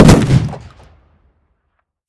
shotgun-shot-5.ogg